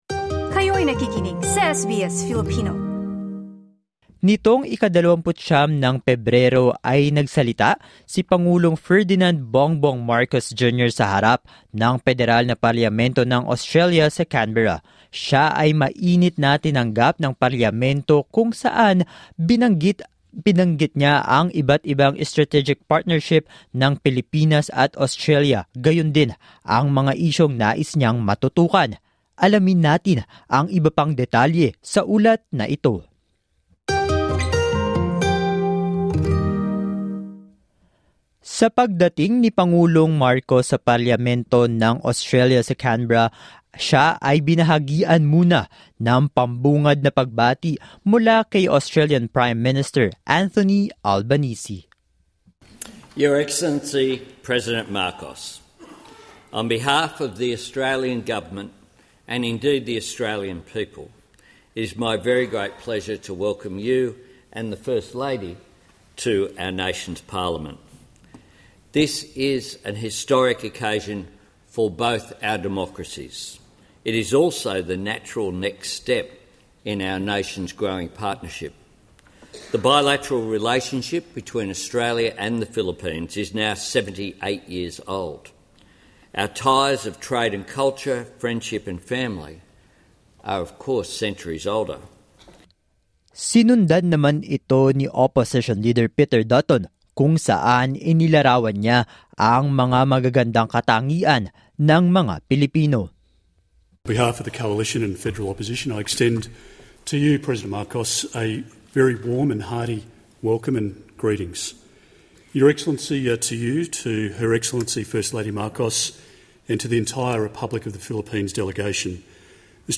SBS News in Filipino, Friday 1 March 2024
The Philippine President Ferdinand "Bongbong" Marcos, Jr. addresses the Australian parliament in Canberra on Thursday, February 22.